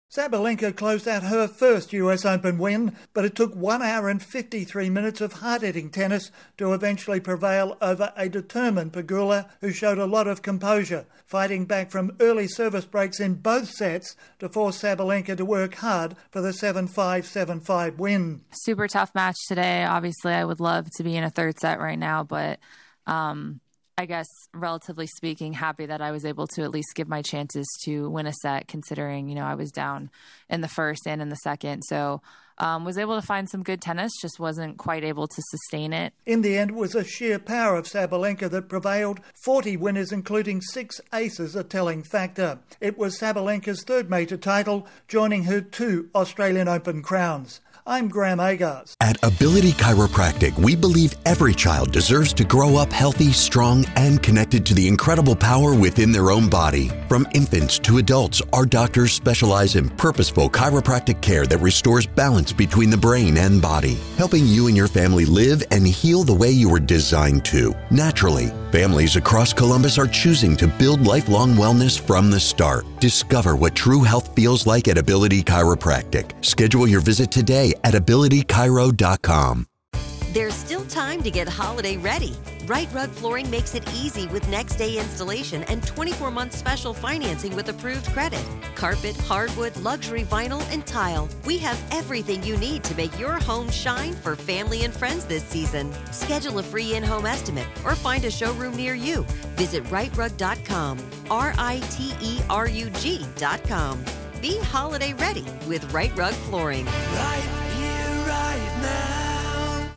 ((NOTE pronunciation of Aryna is ah-REE'-nah, Pegula is peh-GOO'-luh))